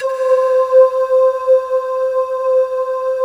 Index of /90_sSampleCDs/USB Soundscan vol.28 - Choir Acoustic & Synth [AKAI] 1CD/Partition D/03-PANKALE